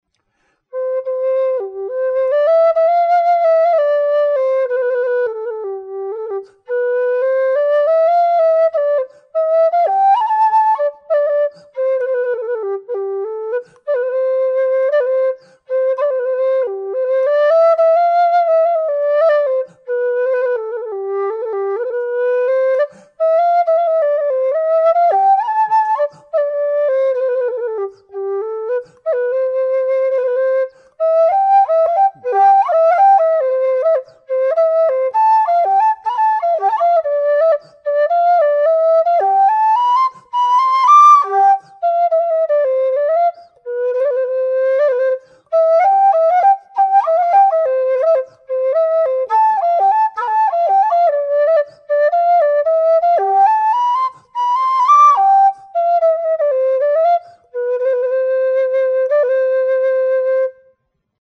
low G whistle
made out of thin-walled aluminium tubing with 18mm bore